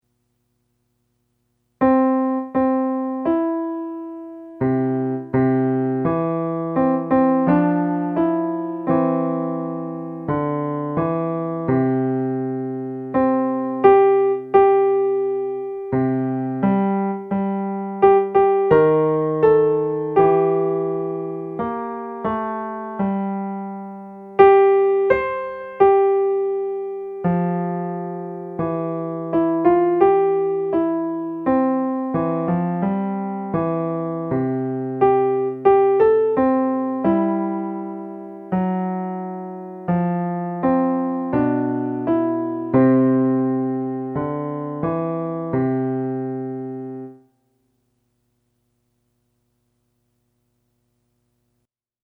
for easy playing by little hands
plus 18 more classic cowboy tunes.